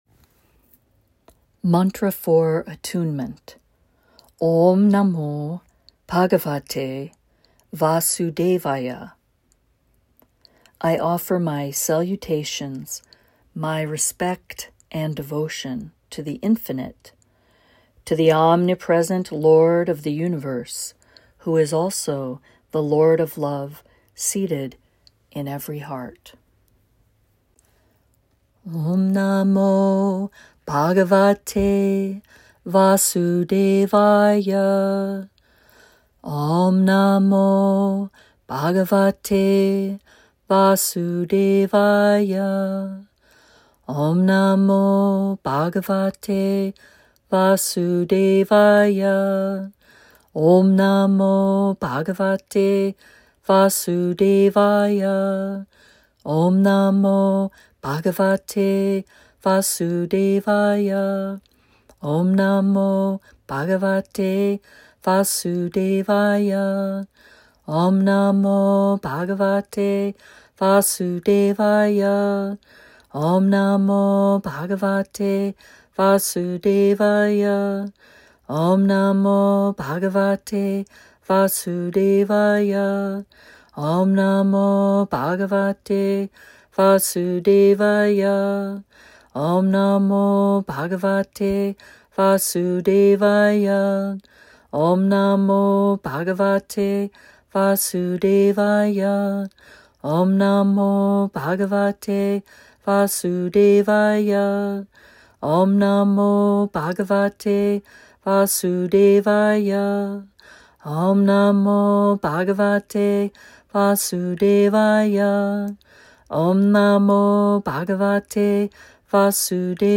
Mantra for Attunement - 108 Rounds